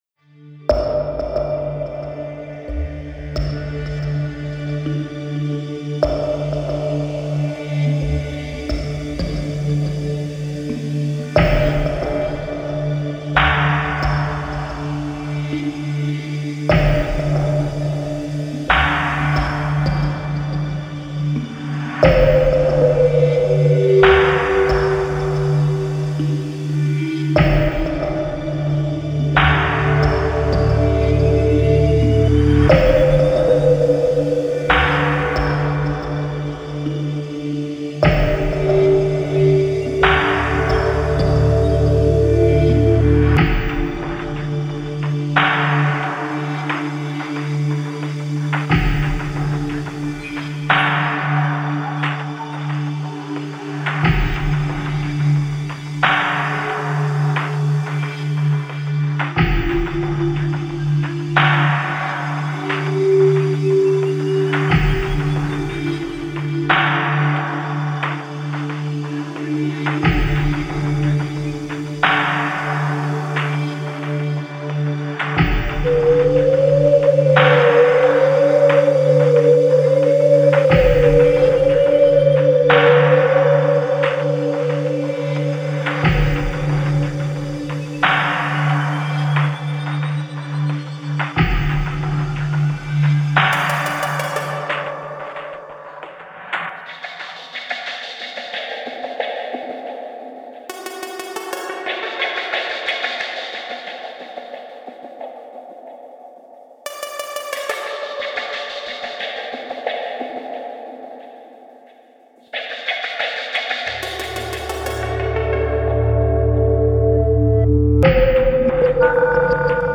恐怖や不安を煽るようなサウンドでダンジョン・迷宮探索シーンを盛り上げるようなBGMです。